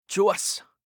알림음 8_좋았어6-남자.mp3